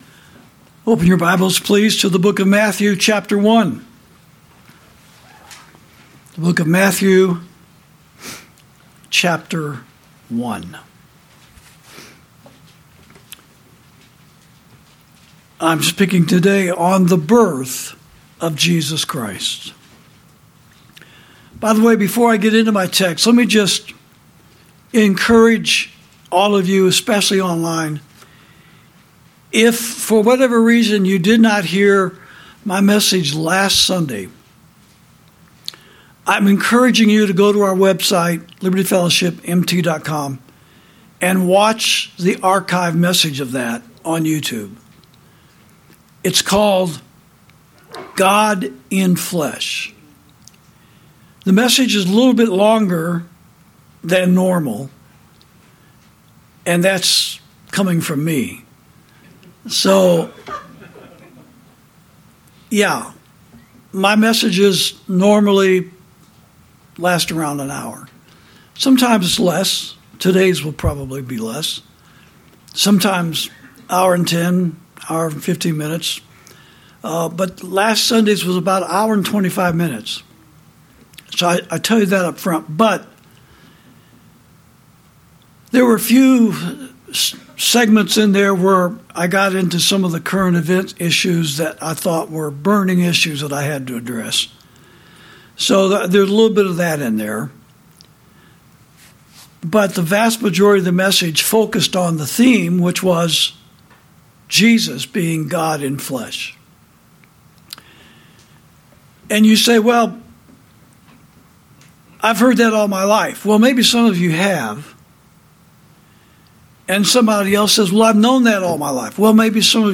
Sermons > The Birth Of Jesus Christ